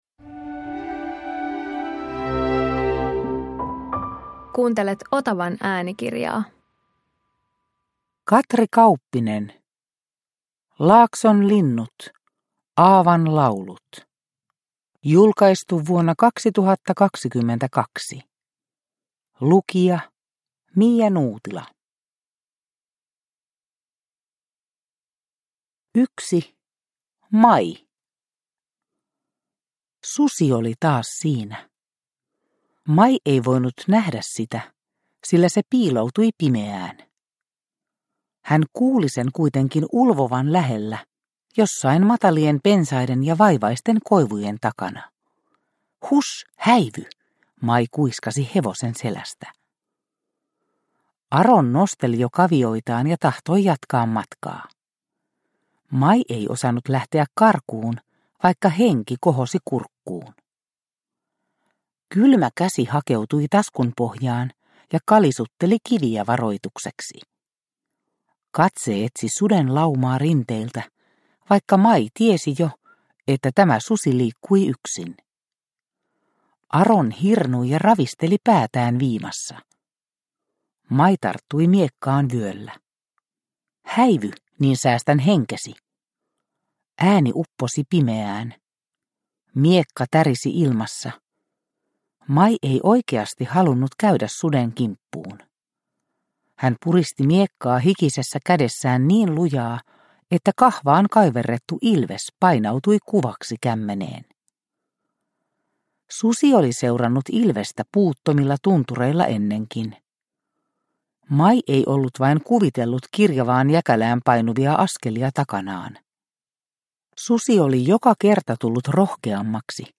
Laakson linnut, Aavan laulut – Ljudbok – Laddas ner